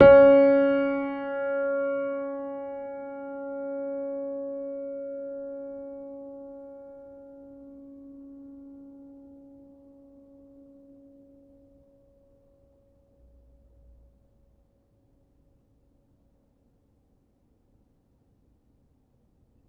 Upright Piano